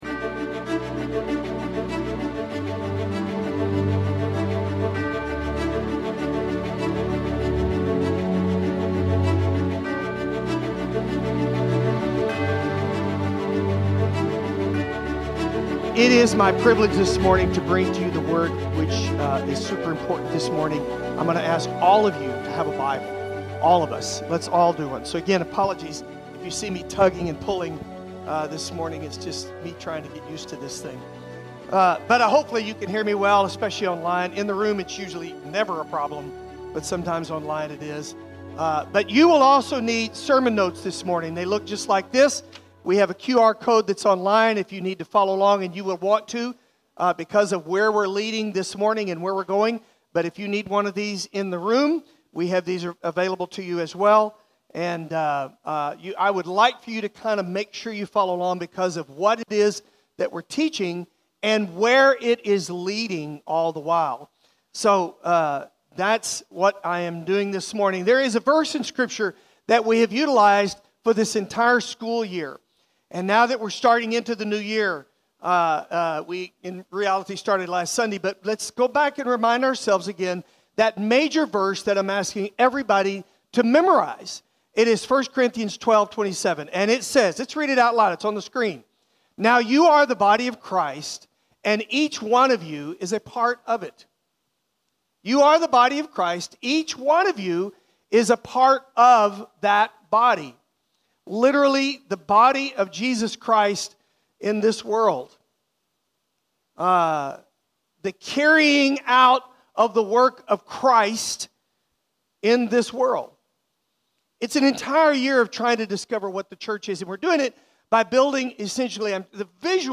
Download the sermon notes (adult version)